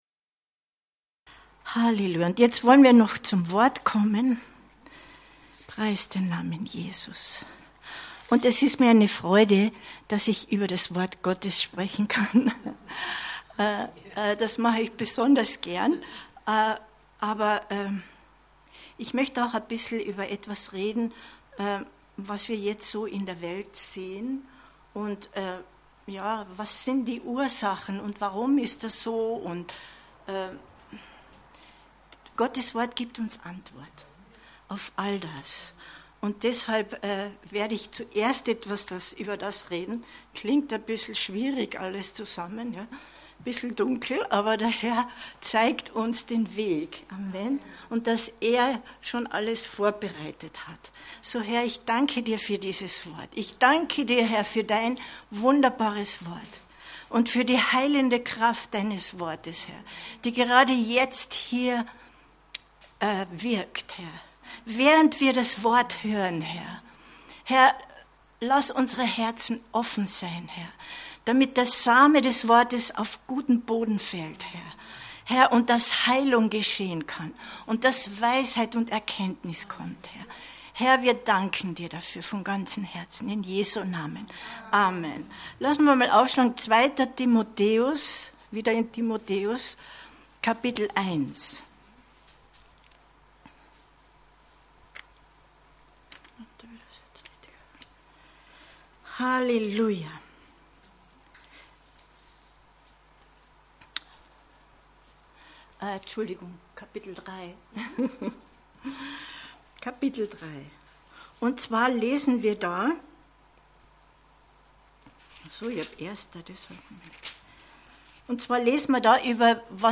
Die heilende Kraft des Wortes Gottes 28.08.2022 Predigt herunterladen